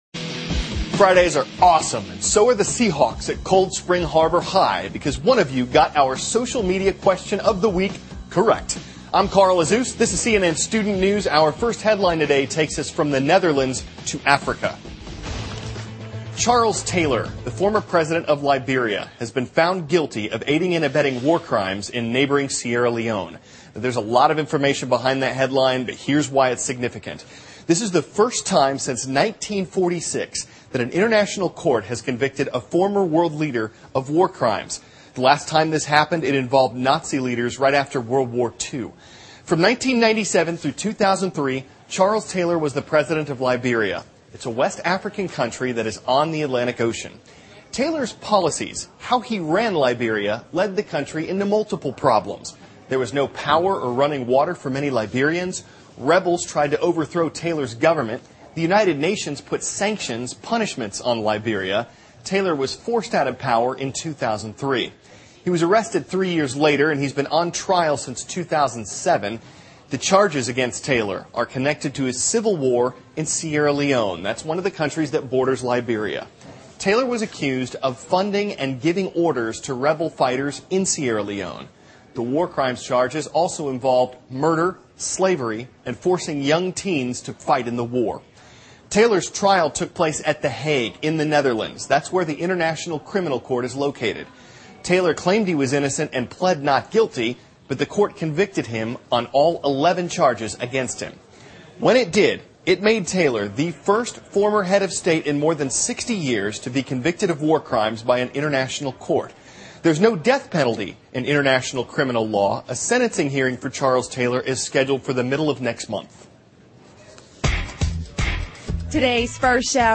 Date:May 02, 2012Source:CNN Editor:CNN News